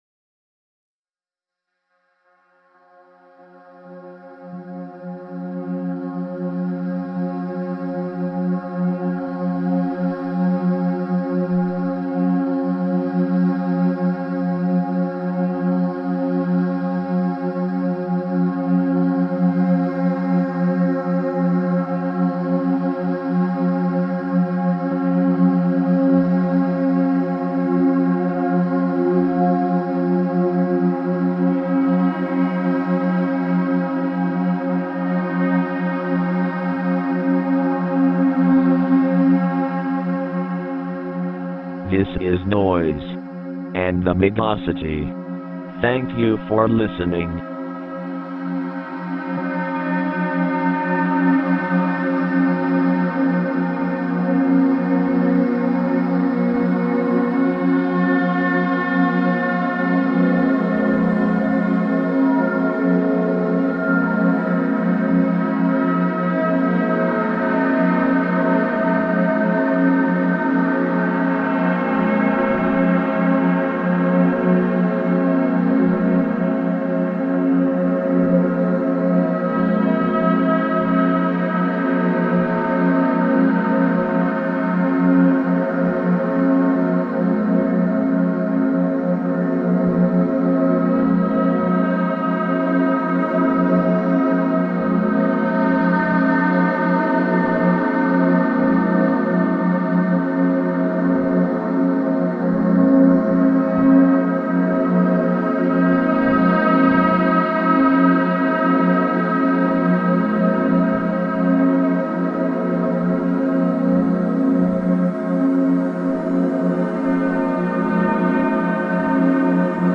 This month on the program, a mix of sleepy-time music and a little bit of noise.